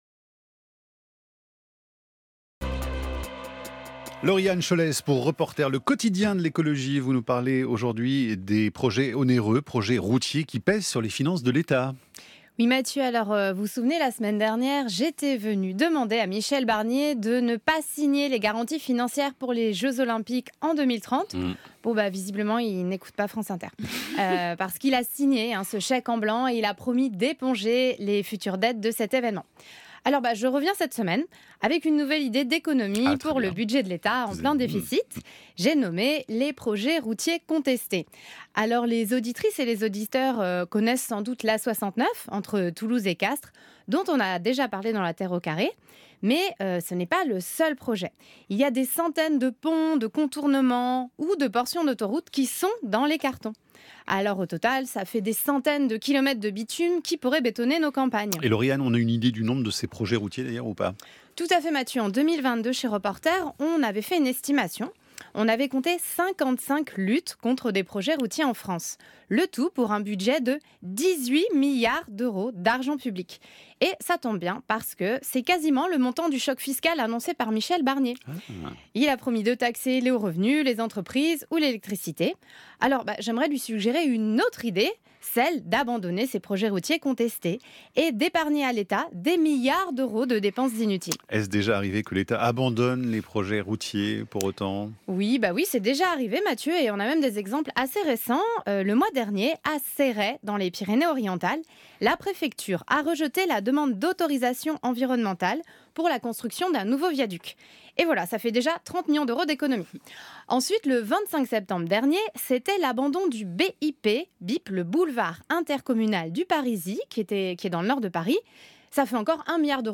ECOUTEZ (3') l'extrait de cette interview de France Inter la Terre au Carré du 7 octobre 2024 sur le coût des projets routiers contestés ou LISEZ notre résumé de l'émission de la Terre au Carré